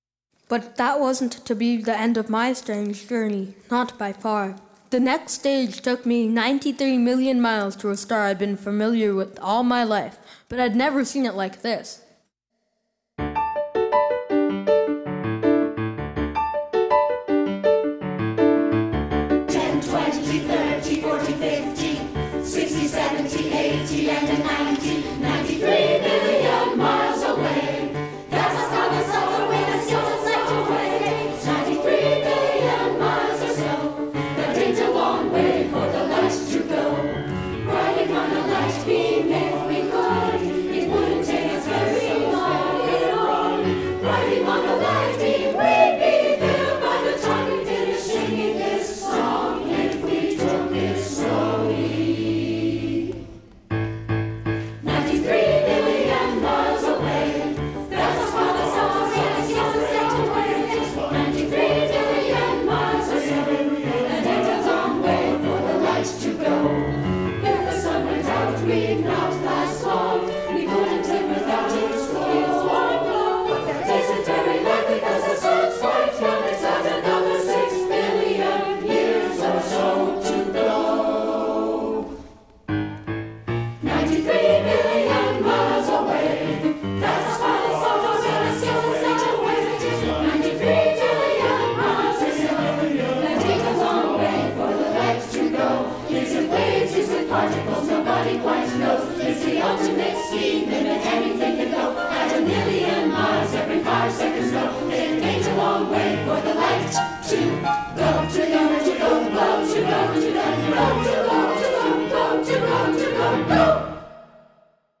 piano
Below are summaries and recordings of the songs of Powers of Ten as performed by the 2014 NCFO Festival Chorus.
• 93 Million Miles – A lively song with a Latin tinge to the accompaniment, full of factual information about the sun and our relationship to it.